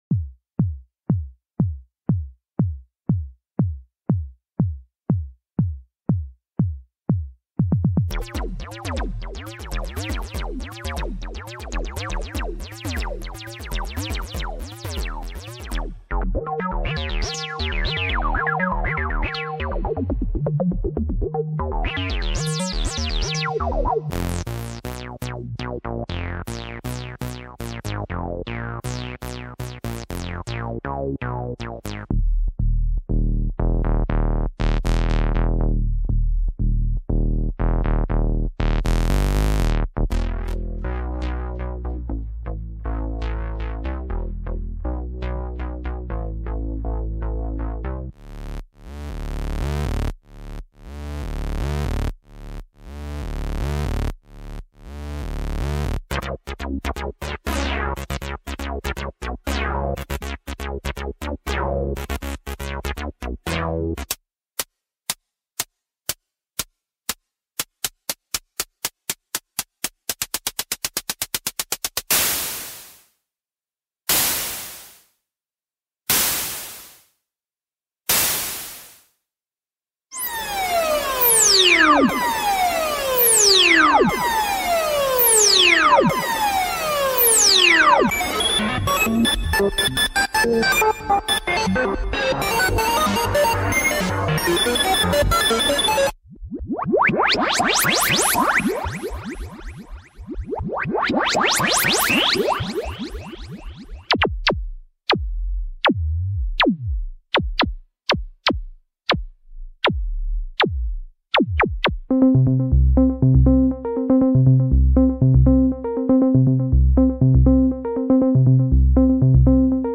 "Bombay" is a pack of 48 Presets for the Roland S-1 synthesizer ideal for Acid, House and Techno.
From Acid sounds, FX, Pads, Leads, Drums, etc. This package is ideal for your projects.